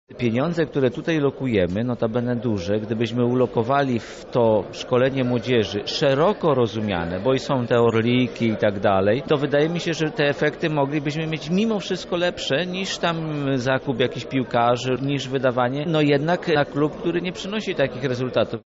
Sprawę komentuje wiceprzewodniczący Rady Miasta, Mieczysław Ryba